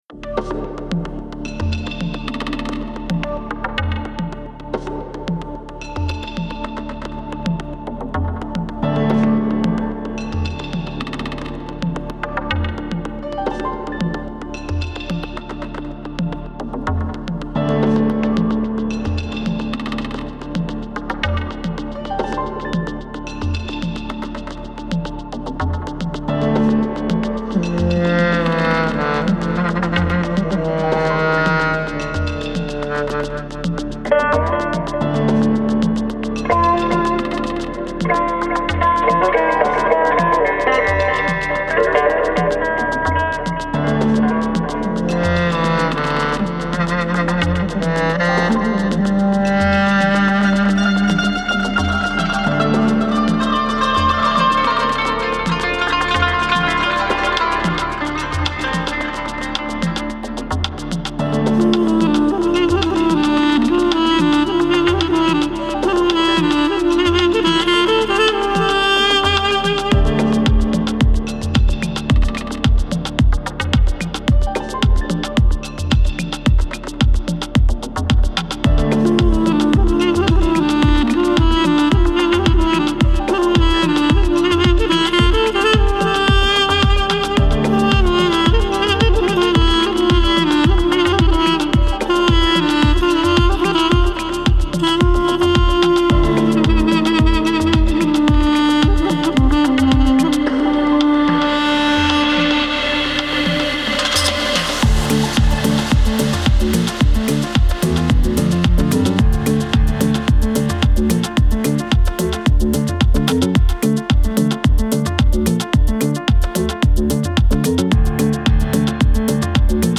الکترونیک , امید‌بخش , بومی و محلی , عصر جدید